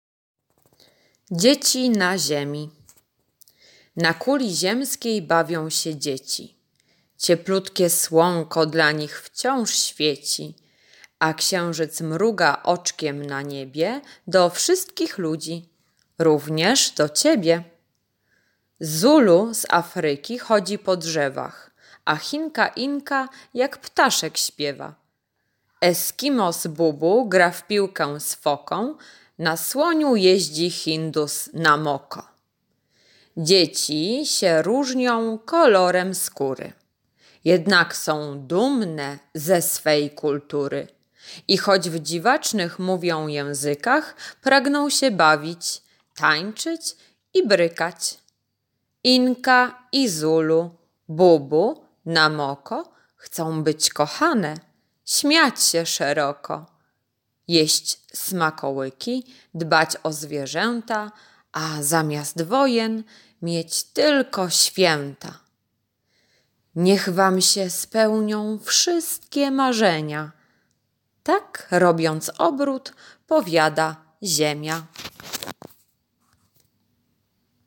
piątek- wiersz [1.19 MB] piątek- zadanie- połącz punkty i pokoloruj obrazek [23.06 kB] piątek- zadanie- znajdź różnicę [84.01 kB] piątek- kolorowanka- przyjaciele [119.70 kB] piątek - ćw. dla chętnych - litera B, b [3.33 MB]